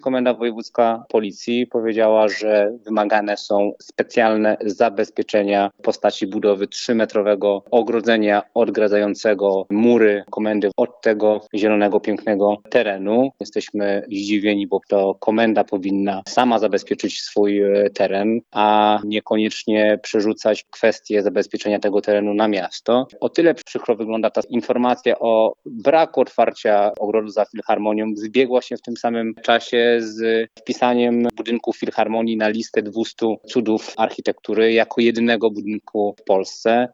Radny Jaskulski dodaje, że będzie zabiegał o dodatkowe środki z budżetu miasta na ustawienie, wymaganego 3-metrowego muru odgradzającego Komendę Wojewódzką od terenu zielonego.